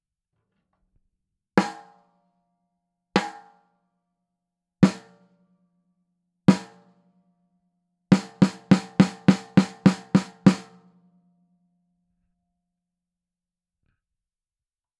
【サンプル音源あり】クジラマイク！SENNHEISER ( ゼンハイザー ) MD421MK2
実際の録り音
スネア
snare.wav